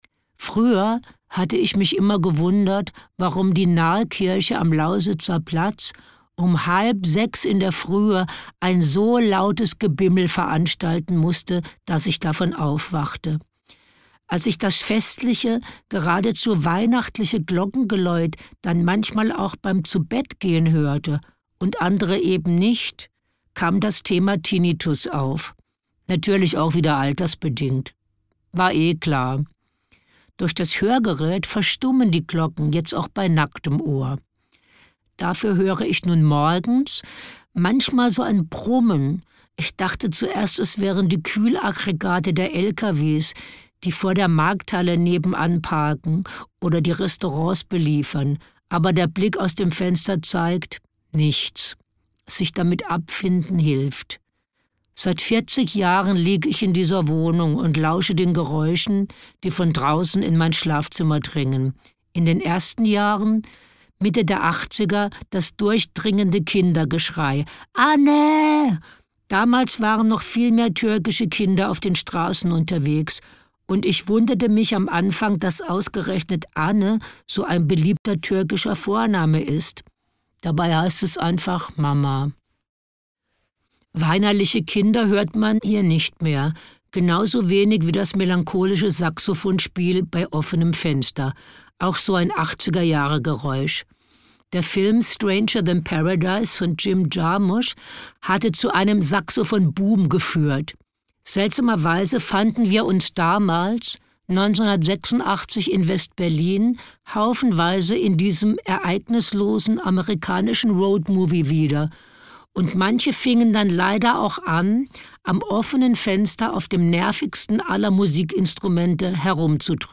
Kein Ratgeber, sondern ein bissig komisches Hörbuch voller Erinnerungen, Gedanken und Geschichten, das am Ende einen ernsthaften Rat bereithält: weniger klagen, mehr leben.
Gekürzt Autorisierte, d.h. von Autor:innen und / oder Verlagen freigegebene, bearbeitete Fassung.